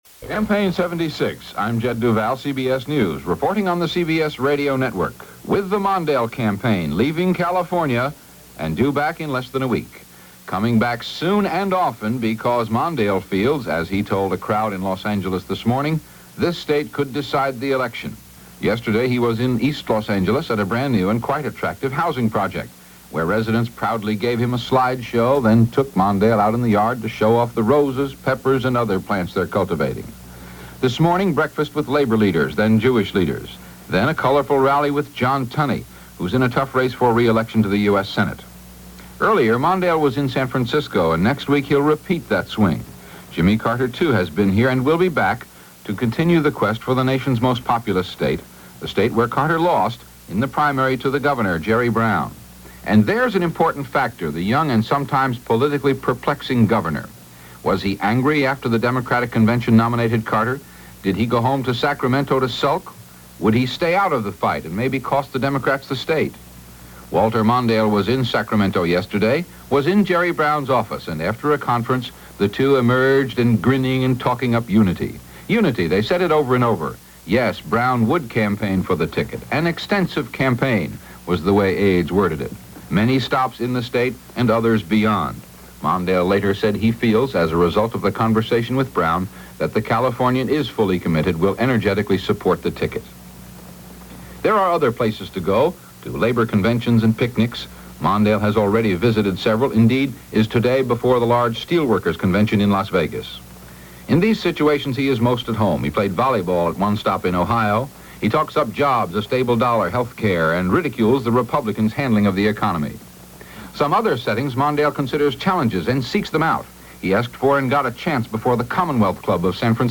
Campaign ’76 – our third installment (as promised) – cover the period of September 1-20, 1976 and as reported by CBS Radio News.